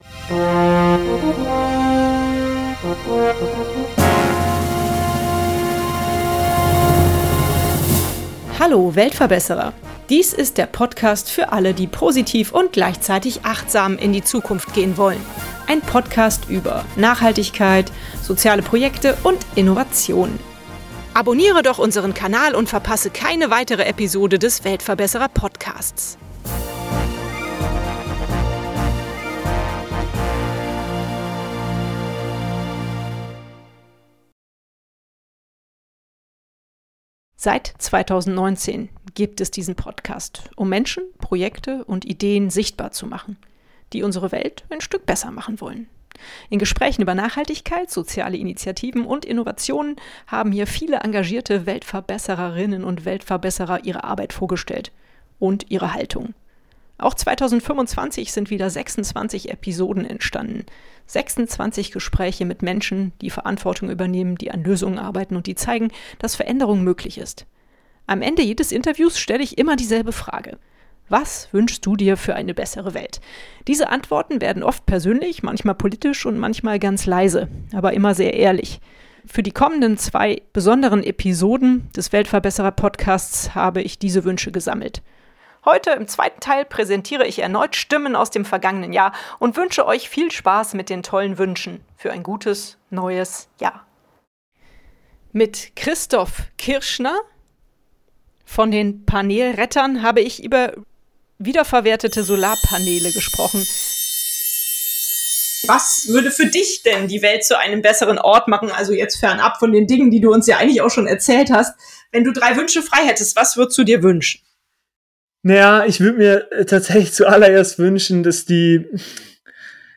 Stimmen aus dem Podcastjahr 2025 – ehrlich, nachdenklich, hoffnungsvoll. Wünsche von Menschen, die Verantwortung übernehmen, Projekte vorantreiben und Veränderung nicht nur fordern, sondern leben.
Diese Folge ist kein klassisches Interview, sondern eine Collage aus Gedanken, Hoffnungen und Visionen.